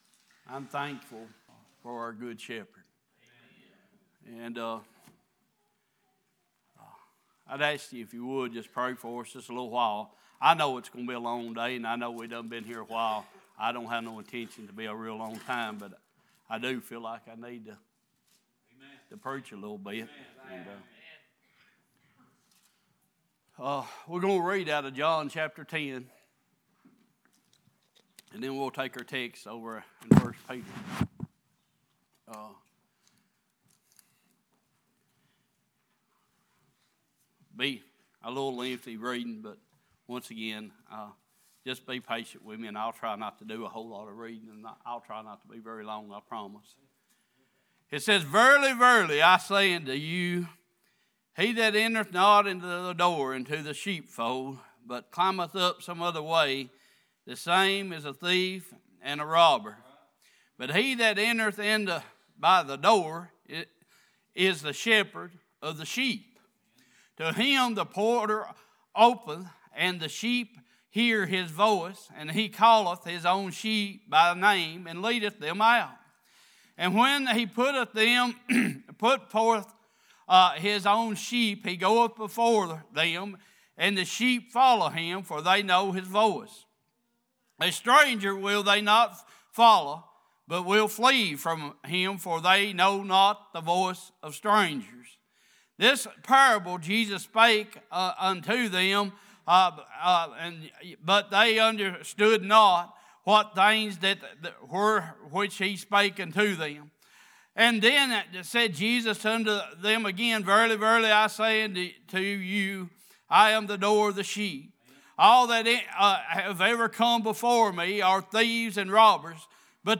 Series: Sunday Morning Passage: John 10:1-16, 1 Peter 2:24-25, 1 Peter 5:4 Service Type: Worship « Faith